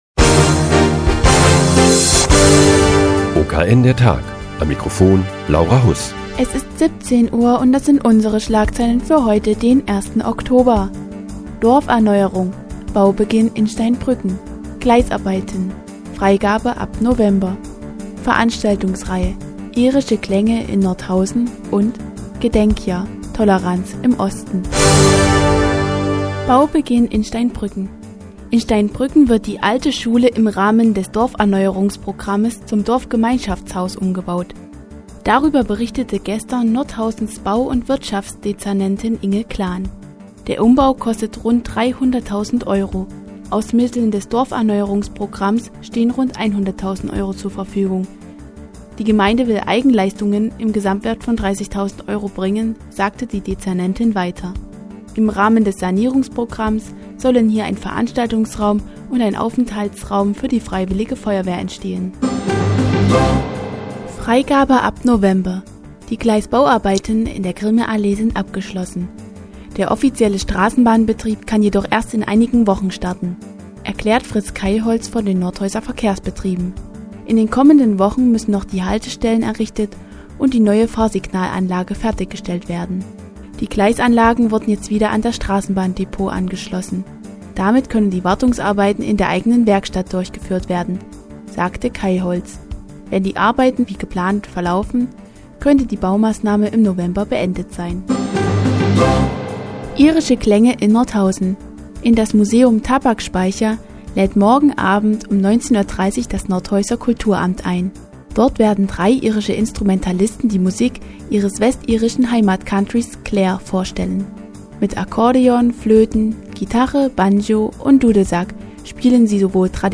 Die tägliche Nachrichtensendung des OKN ist nun auch in der nnz zu hören. Heute geht es um die Gleisbauarbeiten und um irische Klänge im Tabakspeicher.